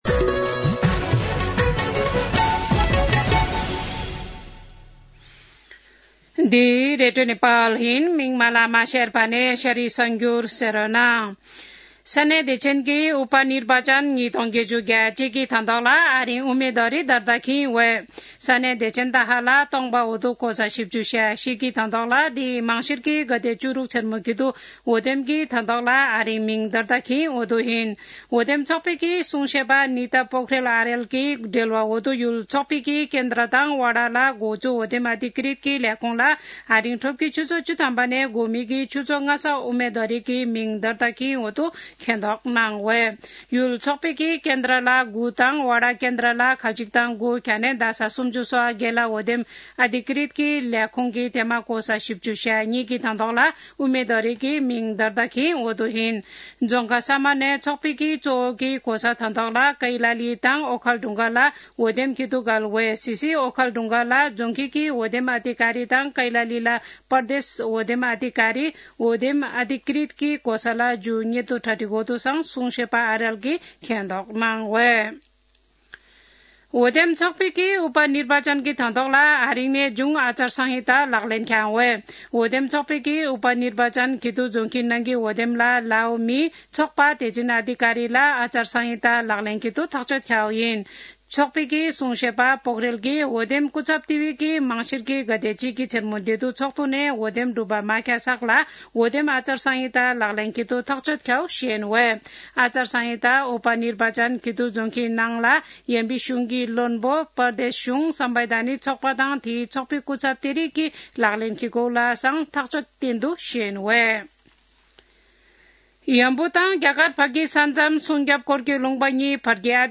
शेर्पा भाषाको समाचार : ३ मंसिर , २०८१
Sherpa-News-1.mp3